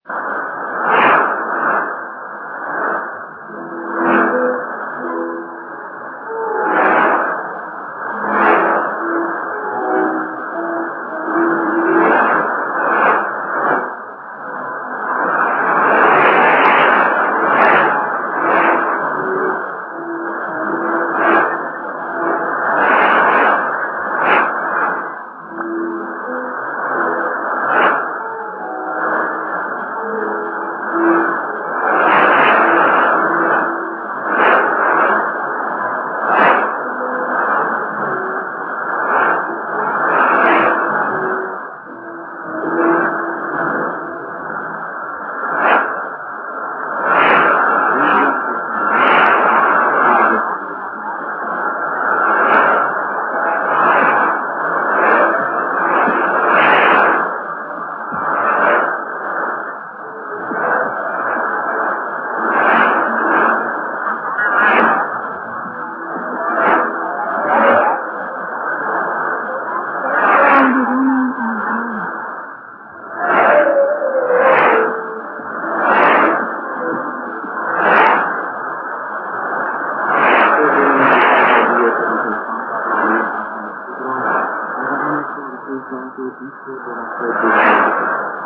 国内向け短波放送です。午前０７:００のISとIDですが、再生開始後４７秒後に"Inja Dushanbe"というSAが出ます。